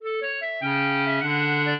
clarinet
minuet6-11.wav